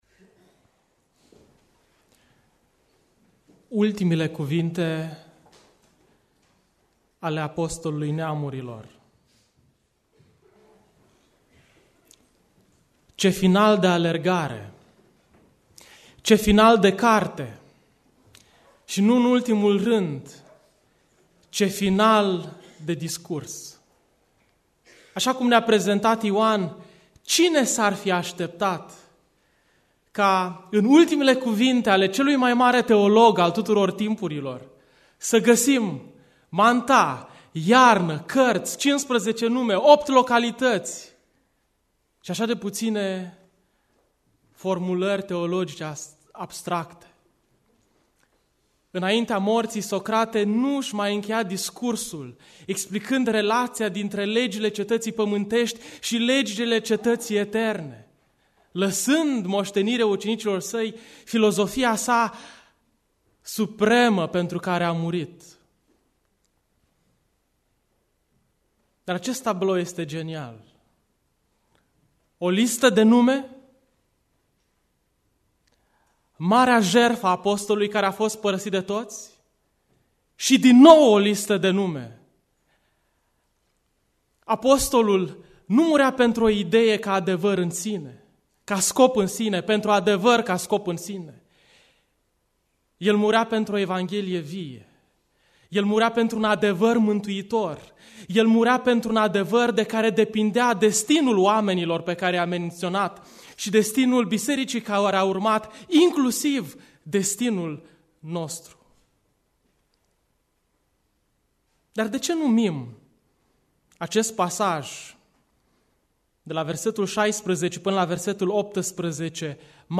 Predica Aplicatie 2 Timotei 4b